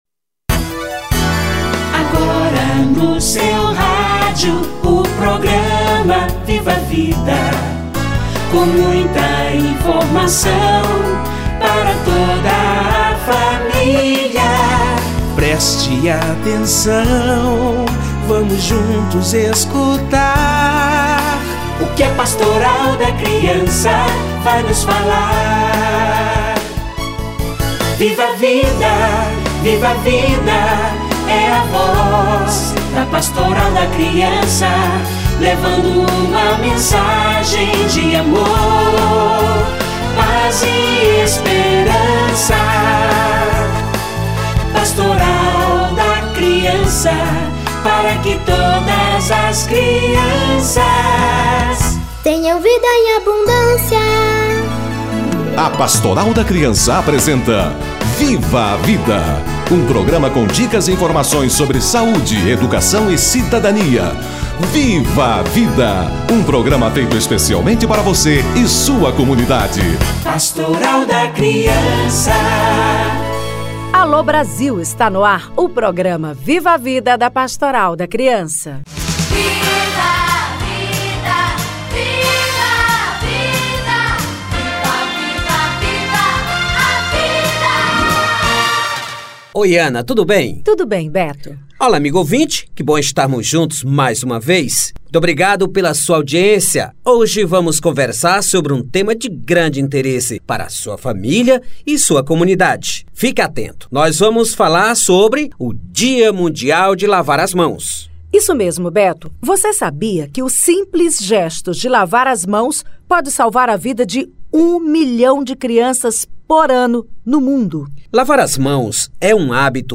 Lavar as mãos - Entrevista